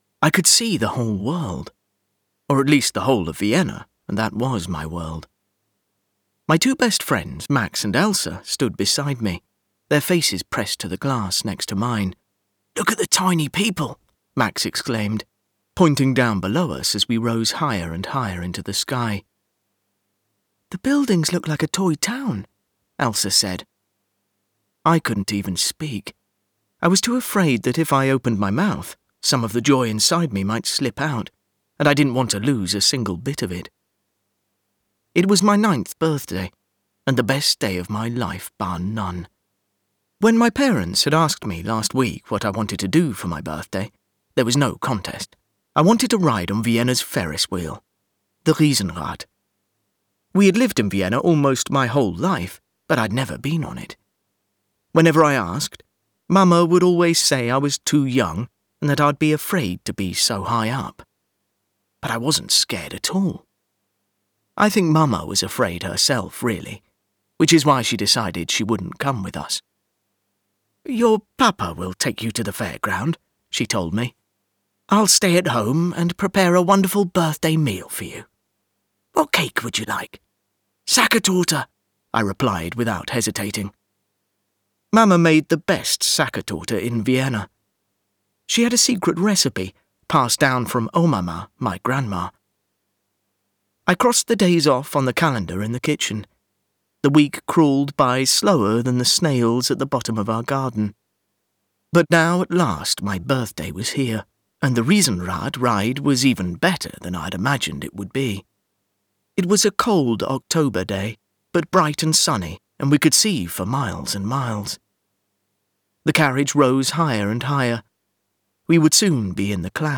Voice Reel
Narration Children's Fiction - When the World Was Ours